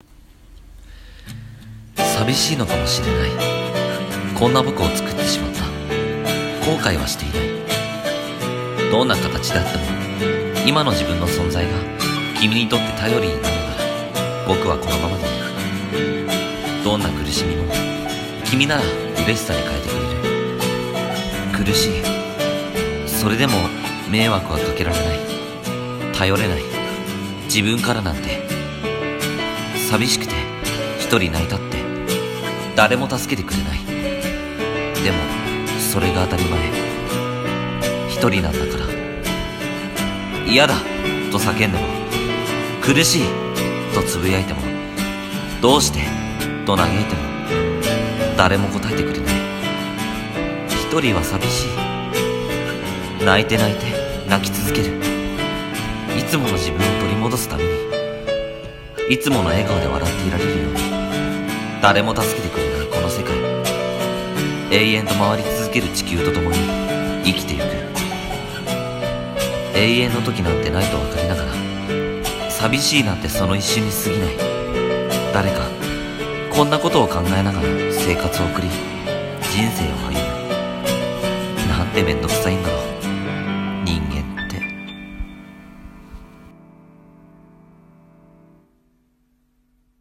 【声劇】自分って､､､